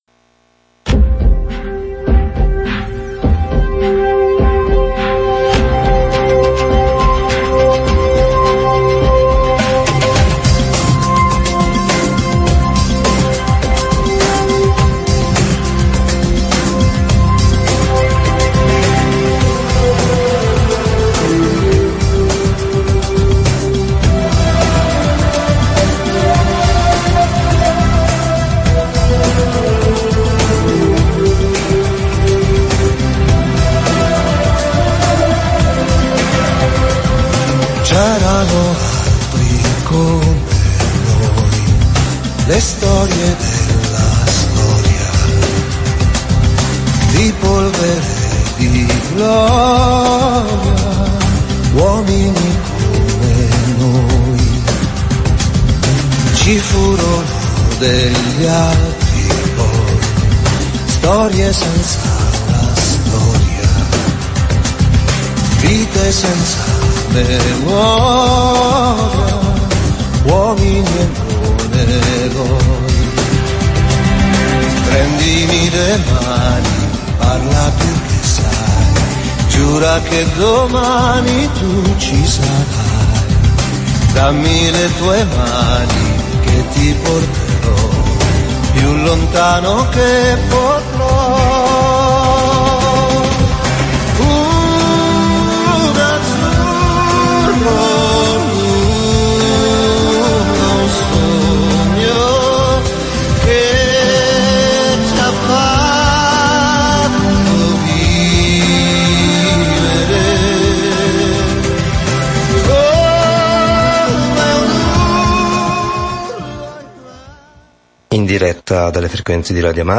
Rubrica a cura di Acli Terra